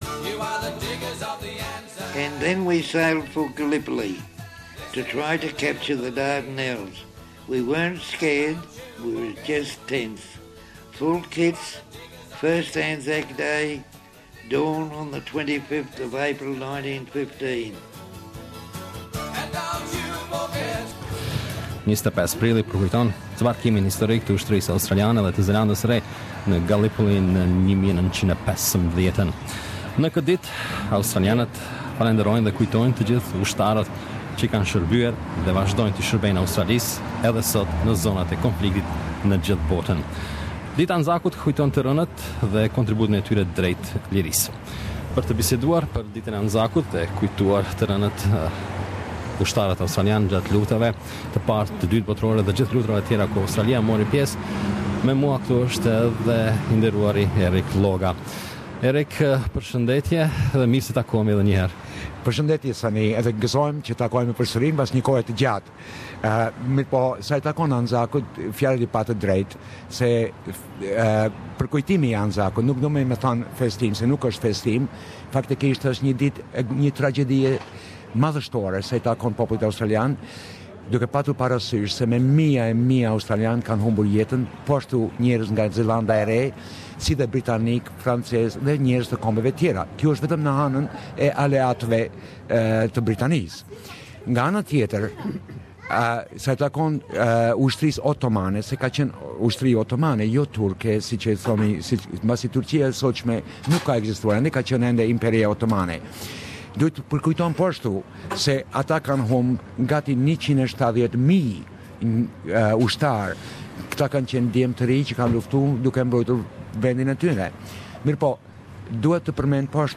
ANZAC Day - Interview